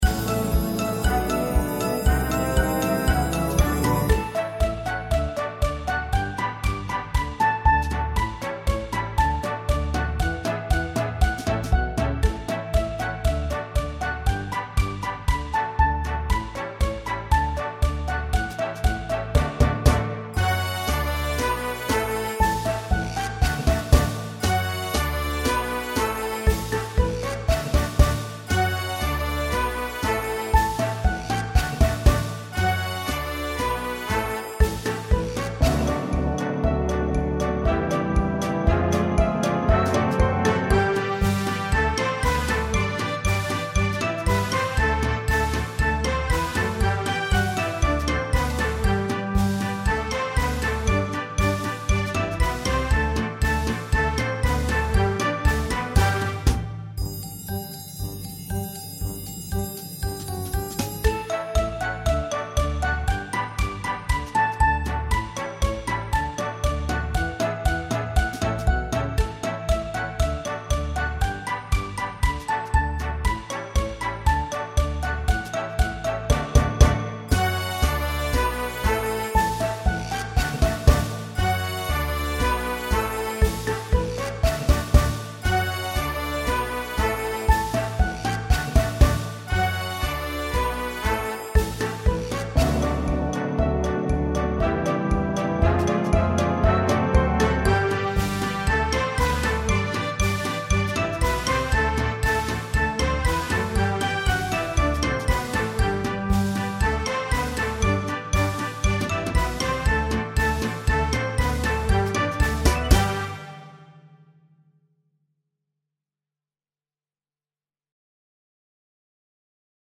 10 Can Can (Violin Backing Track